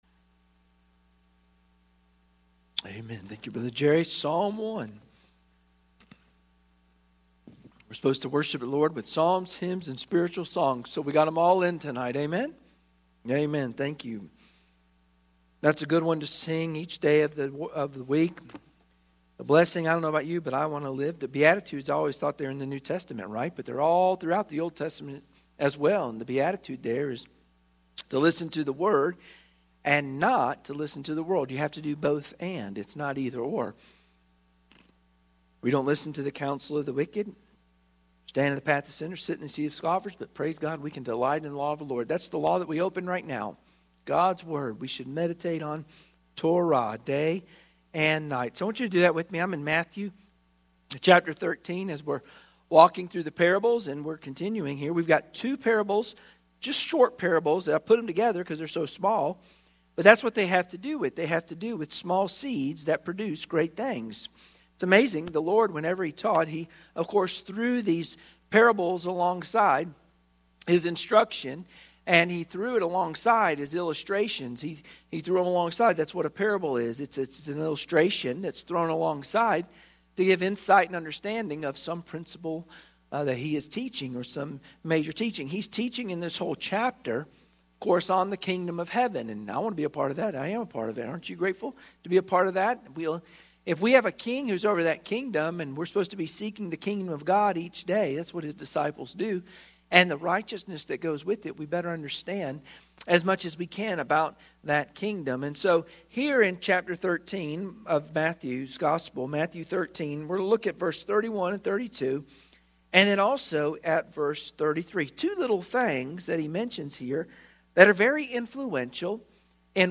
Sunday Evening Service Passage: Matthew 13:31-32,33 Service Type: Sunday Evening Worship Share this